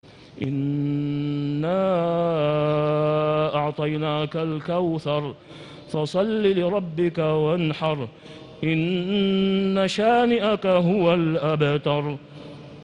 سورة الكوثر > السور المكتملة للشيخ أسامة خياط من الحرم المكي 🕋 > السور المكتملة 🕋 > المزيد - تلاوات الحرمين